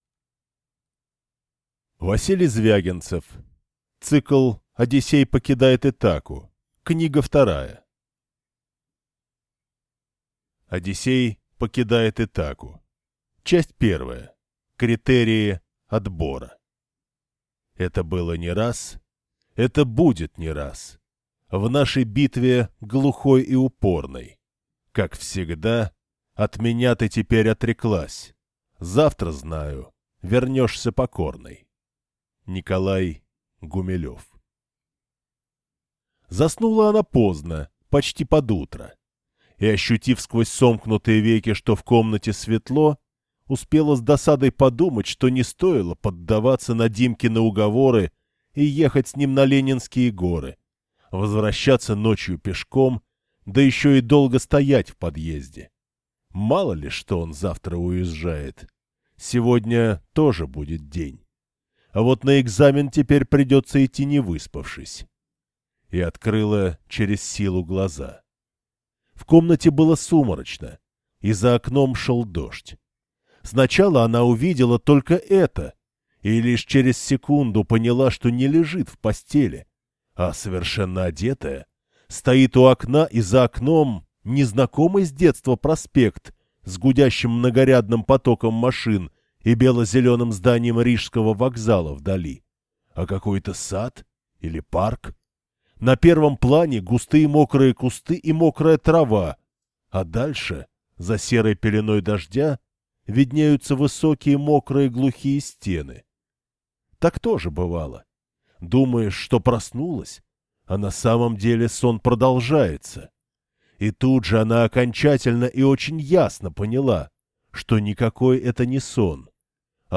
Аудиокнига Одиссей покидает Итаку | Библиотека аудиокниг